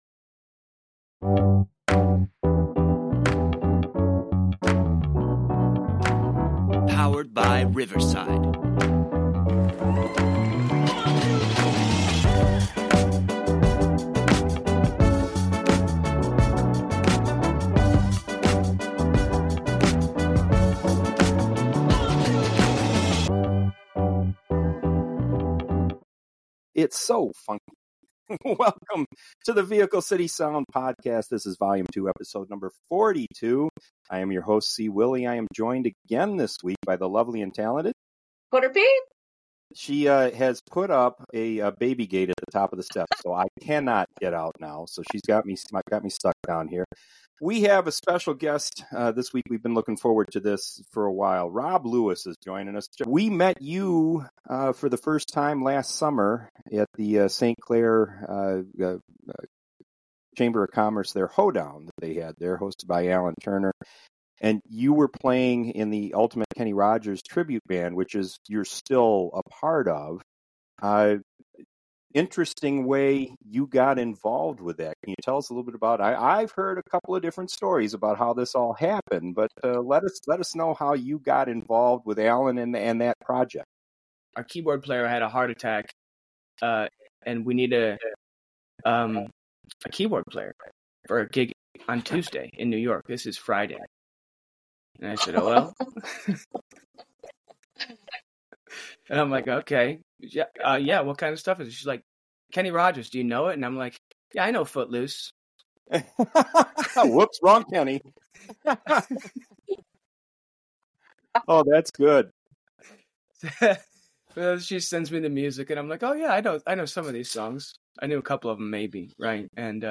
but he shines brightest behind the keyboard
soulful sounds